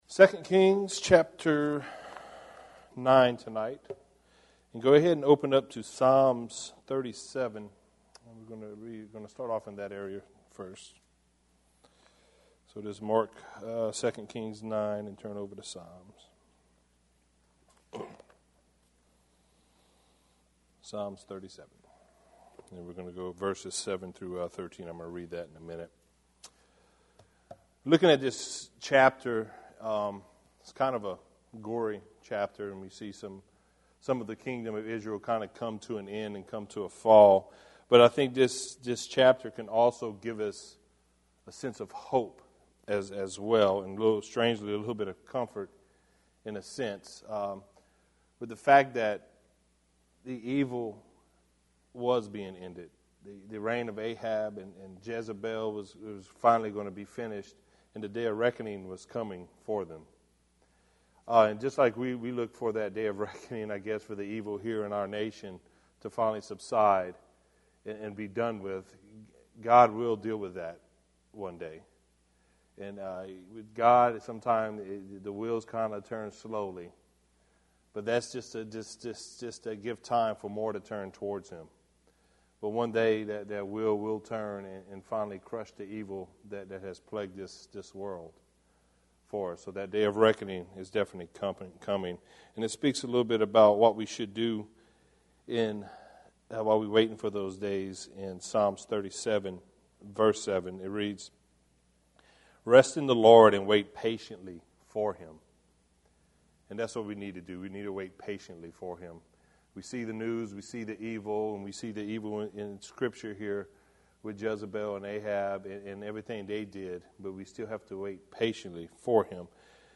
Join us for this verse by verse study in the book of 2 Kings